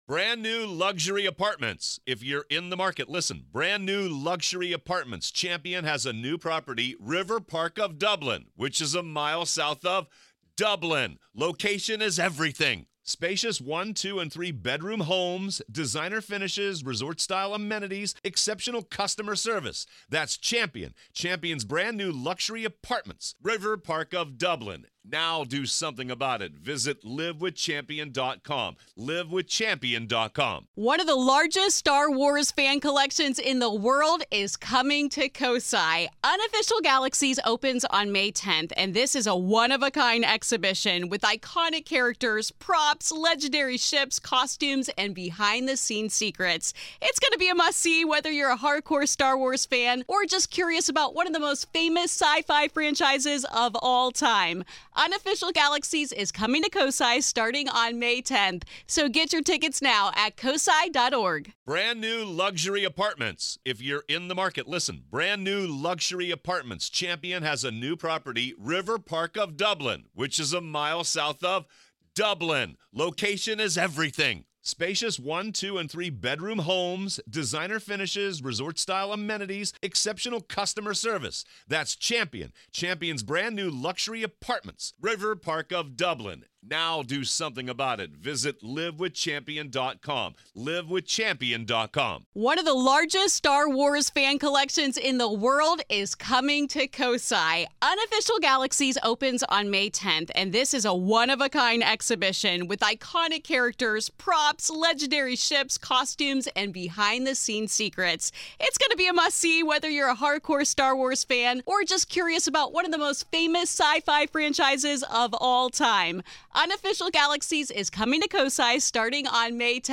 In this classic episode, a conversation